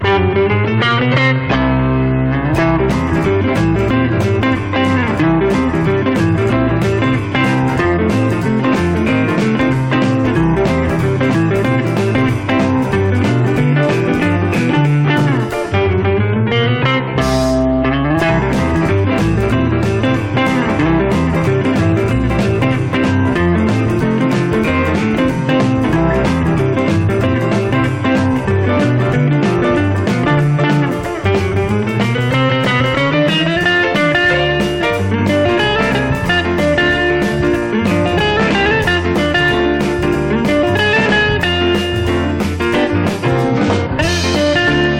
Rock, Pop　France　12inchレコード　33rpm　Stereo